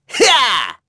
Neraxis-Vox_Attack3.wav